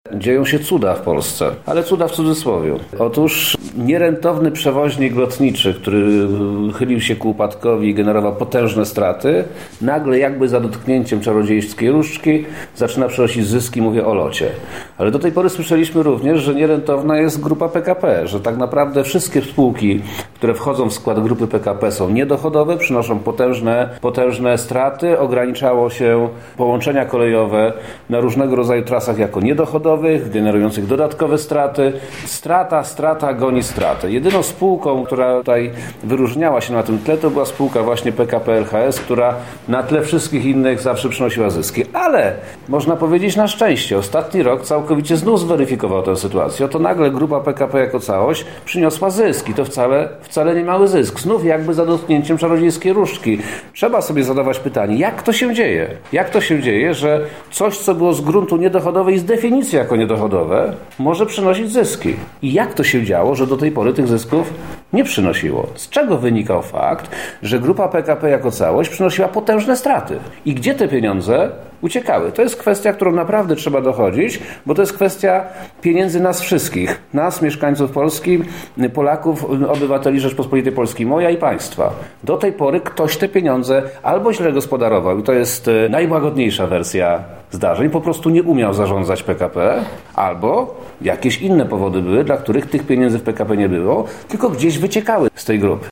Wojewoda lubelski Przemysław Czarnek podkreśla, że nie tylko ta spółka zanotowała taki wzrost.